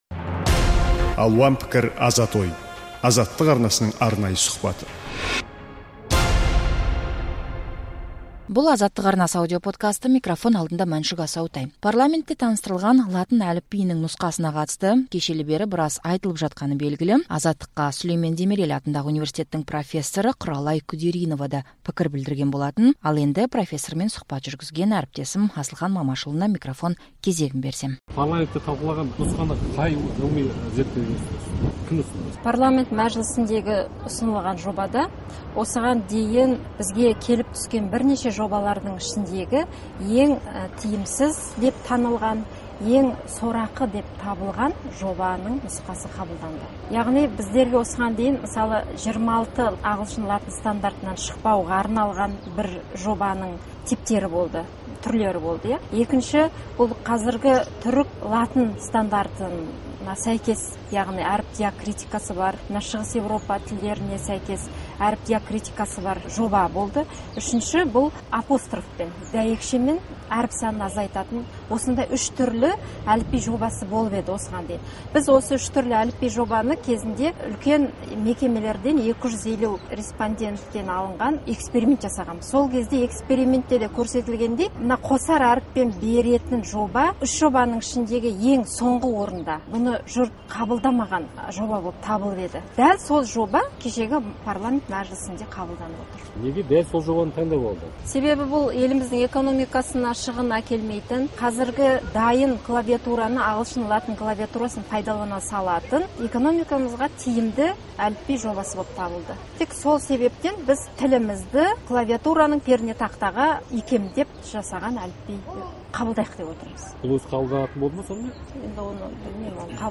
сұқбат.